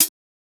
Metro HC4.wav